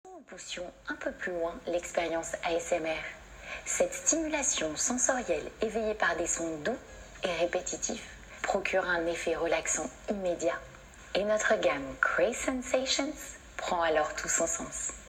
Bandes-son
Craze sensations / canal toys / voix off